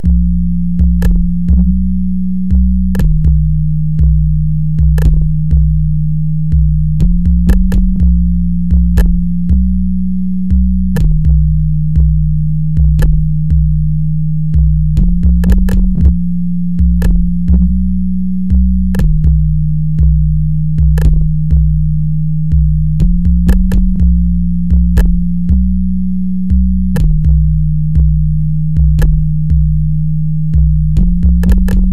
平滑节拍循环120
描述：简单的Hip Hop节拍，在TR808上以120bpm的速度进行。
Tag: 120 bpm Hip Hop Loops Drum Loops 5.37 MB wav Key : F